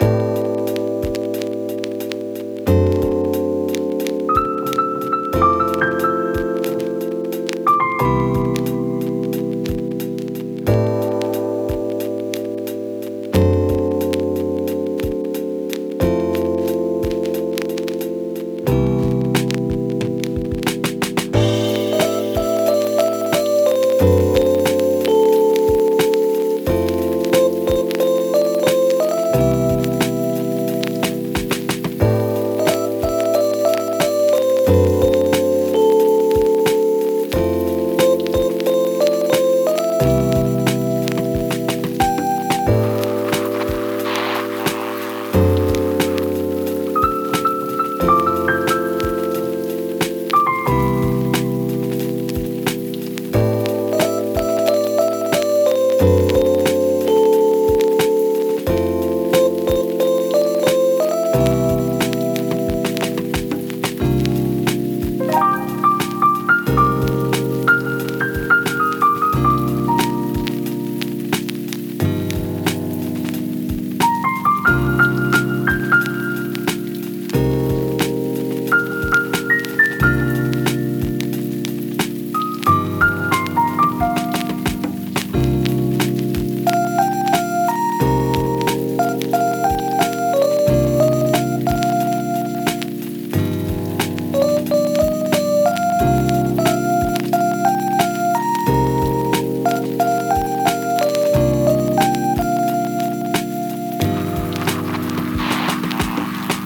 レトロな楽曲
【イメージ】Lo-Fi Hip Hop、チル、雨の日の夜、ゆったり など